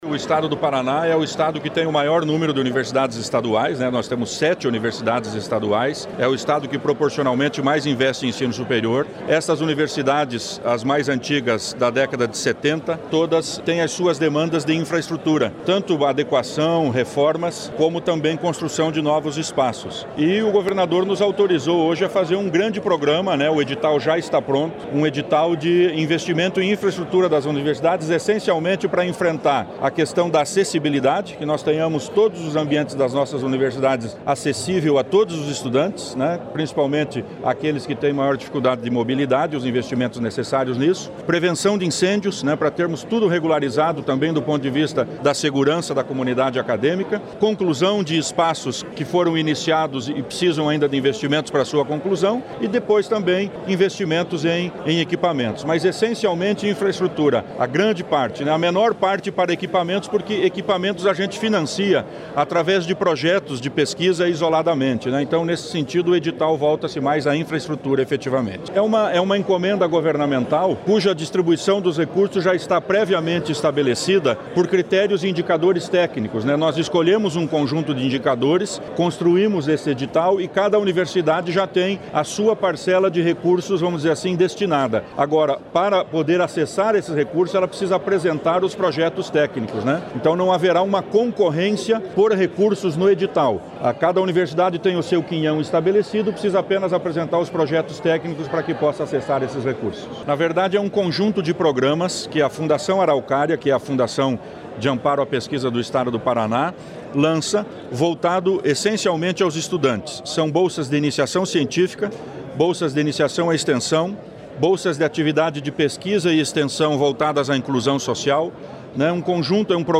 Sonora do secretário de Ciência, Tecnologia e Ensino Superior, Aldo Bona, sobre a liberação de R$ 212 milhões para obras em universidades estaduais e bolsas de estudo | Governo do Estado do Paraná